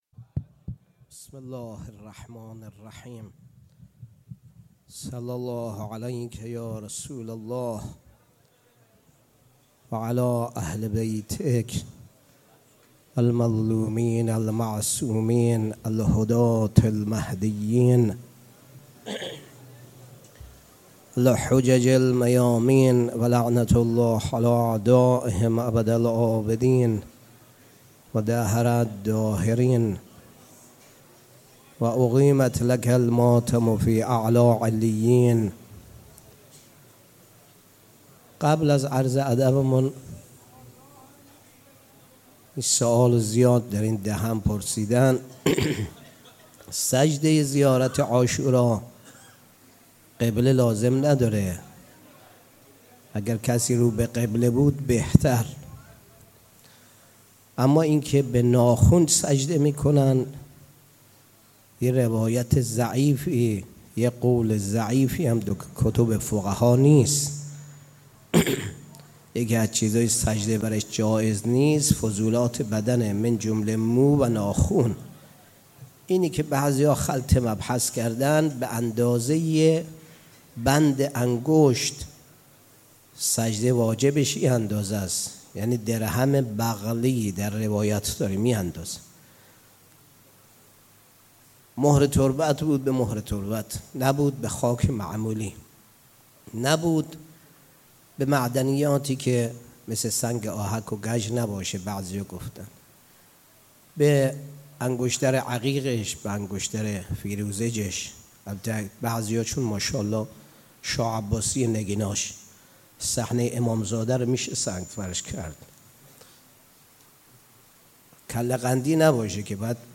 شب تاسوعا محرم 96 - کربلای معلی - سخنرانی